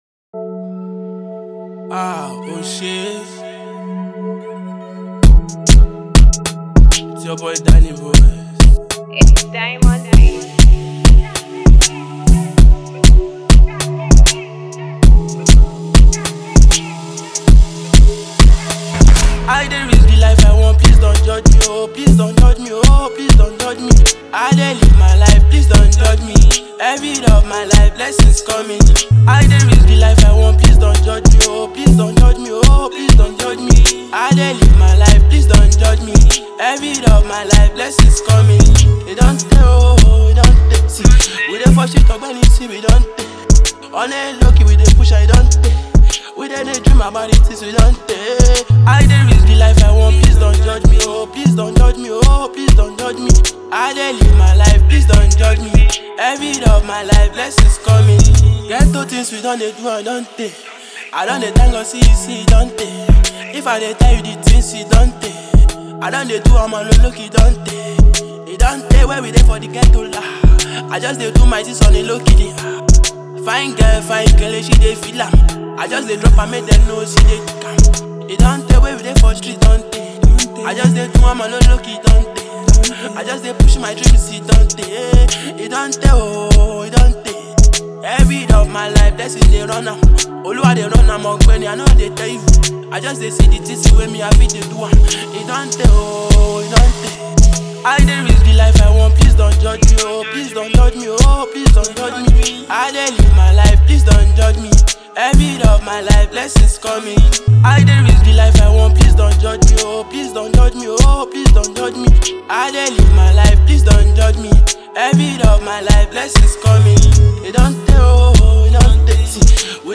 With a captivating melody and enchanting lyrics